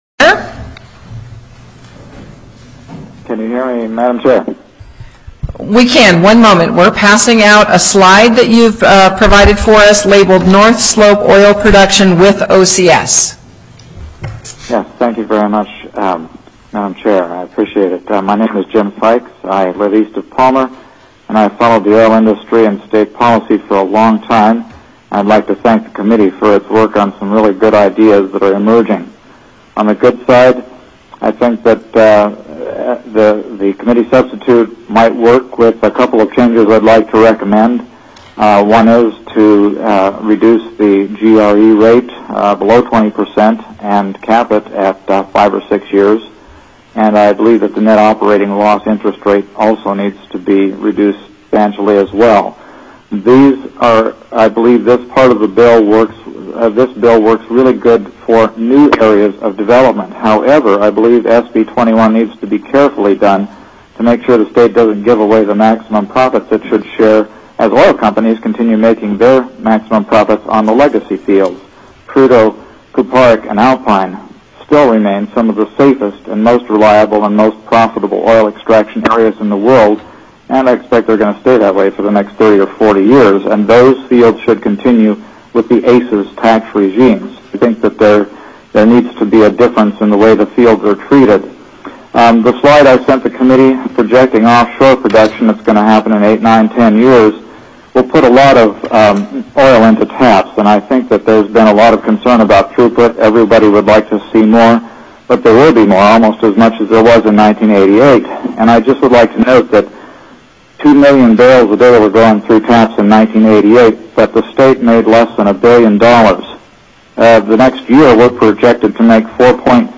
-- Public Testimony --